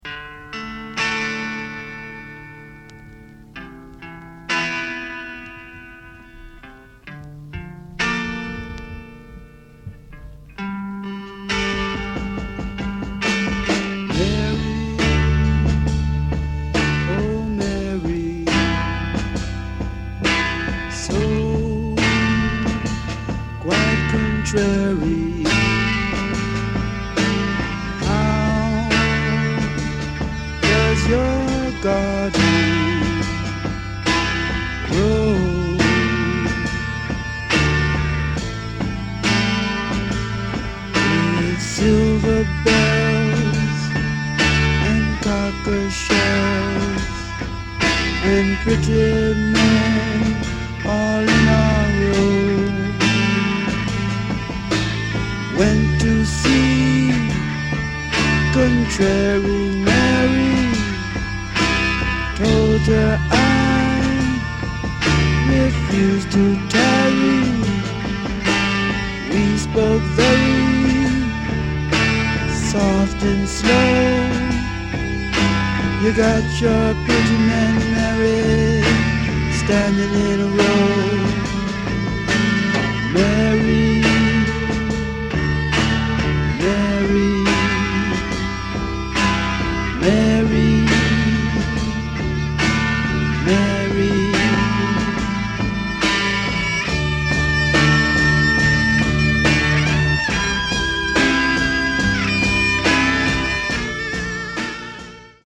The Priogressive/ Kraut Rock overlords 1st LP!